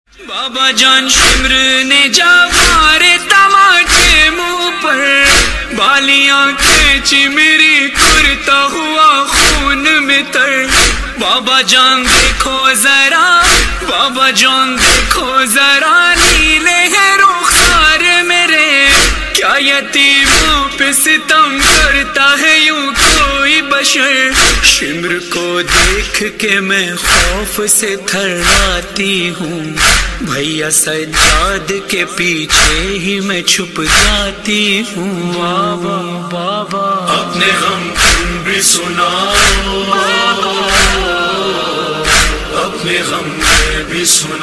Category: Islamic Ringtones